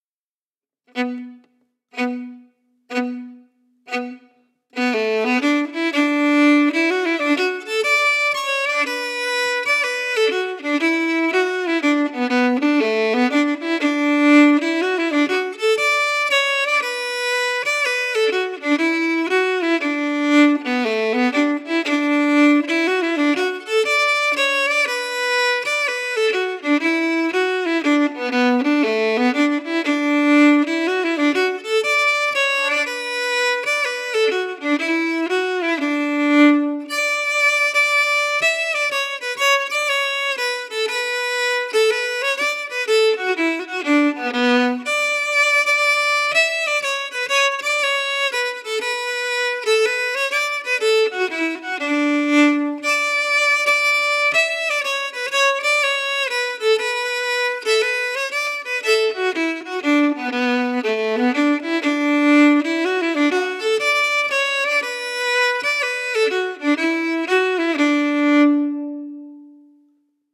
Key: D
Form:Slow reel
Played slowly for learning